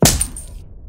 chain-break3.mp3